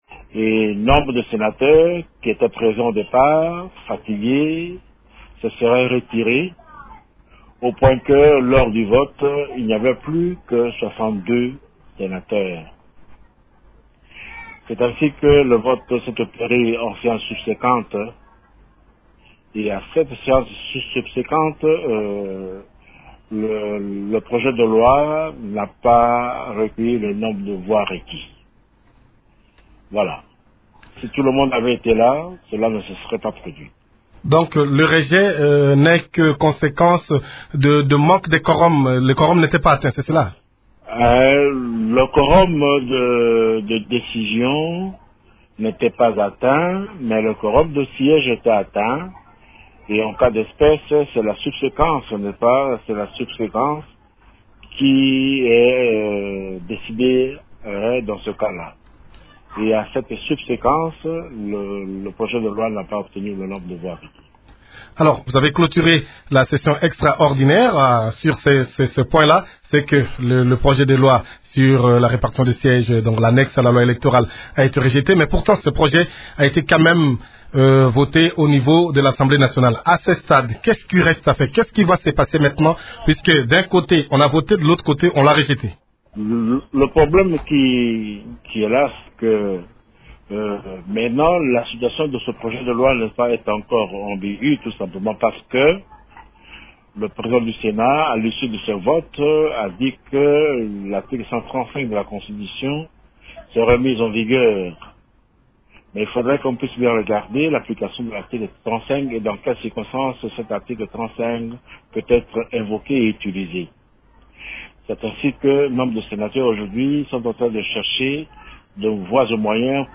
D’autres explications du président de la commission PAJ qui fournit sur cette question sont à écouter dans cet extrait sonore.